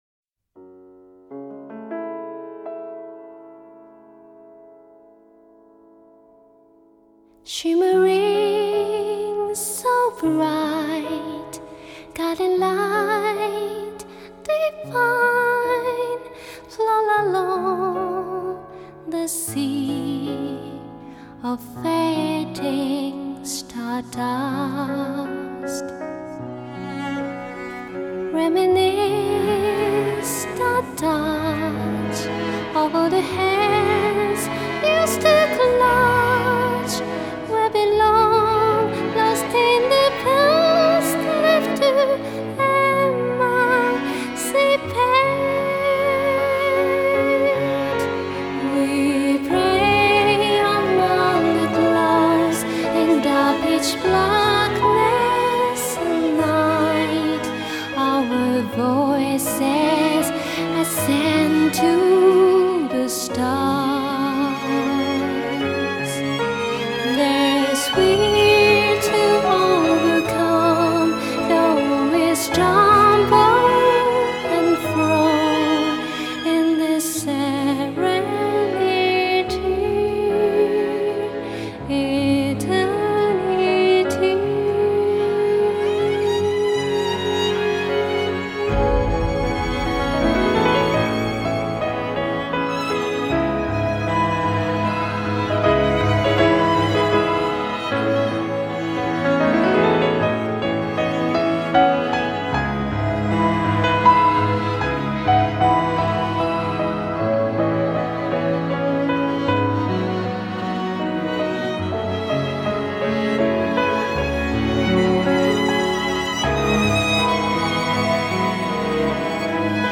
C'est une ballade douce et légère, très agréable !